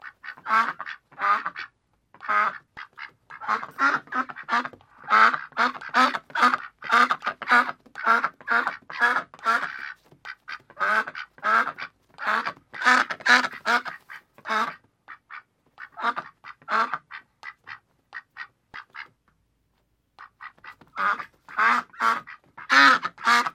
quack-sound